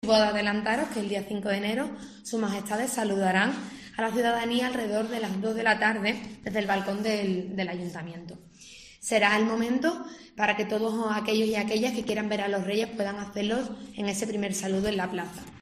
Lola Cazalilla, concejala del Ayuntamiento de Cádiz sobre la cabalgata
La delegada de Cultura y Fiestas, Lola Cazalilla, ha informado hoy en rueda de prensa de los aspectos más relevantes de la Cabalgata de Reyes Magos que recorrerá el próximo 5 de enero la avenida principal llegando hasta la plaza San Juan de Dios.